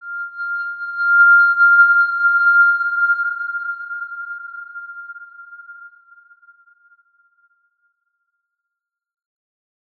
X_Windwistle-F5-pp.wav